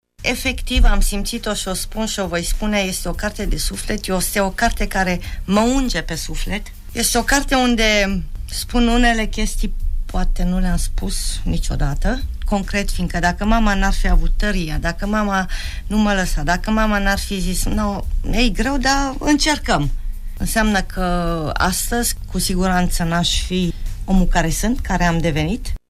Ecaterina Szabo în exclusivitate pentru Radio Tg. Mureș: